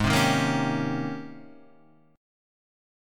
G# Major 11th